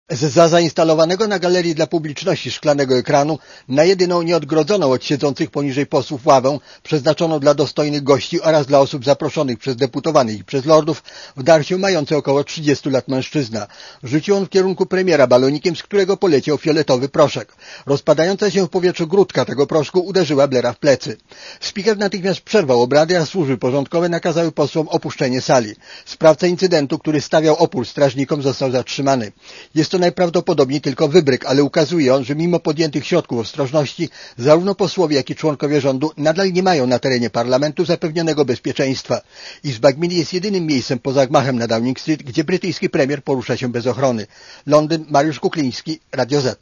Posłuchaj relacji londyńskiego korespondenta Radia ZET (181 KB)